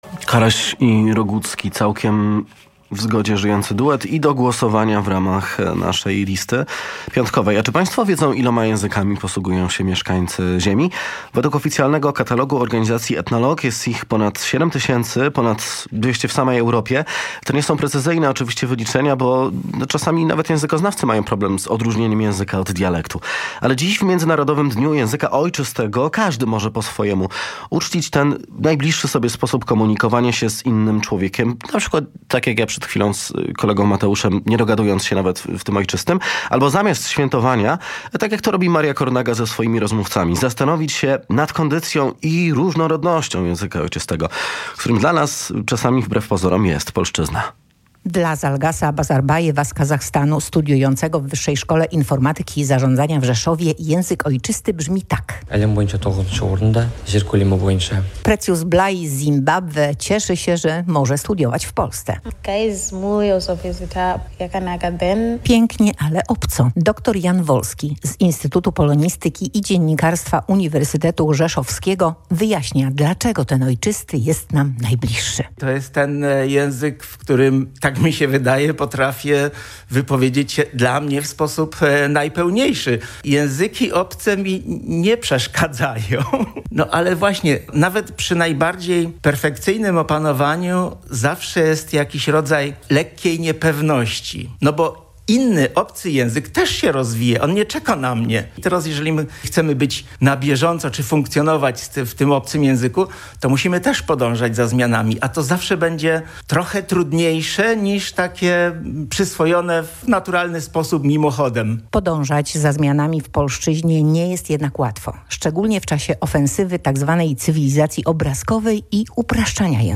O swoich krajach, potrawach, tęsknocie za domem opowiedzieli w swoim ojczystym języku studenci WSIiZ pochodzący z Zimbabwe i Kazachstanu. Audycja odbyła się w ramach Międzynarodowego Dnia Języka Ojczystego.